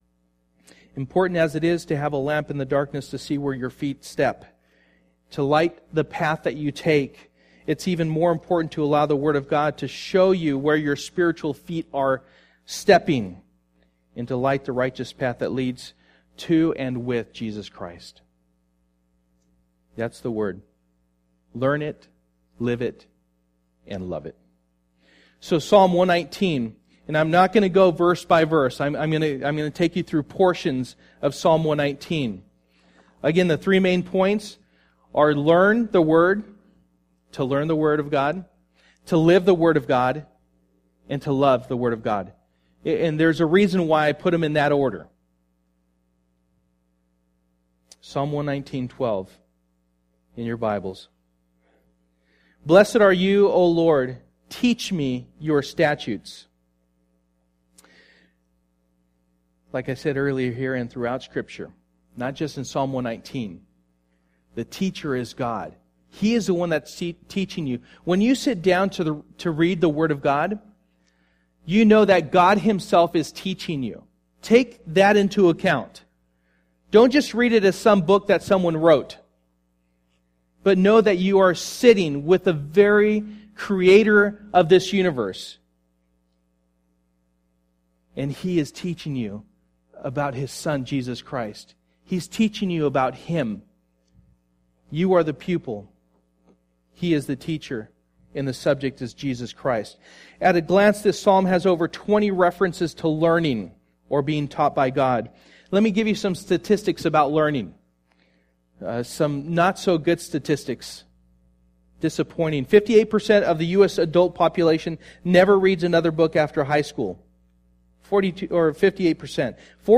Passage: Psalm 119:1-176 Service: Sunday Morning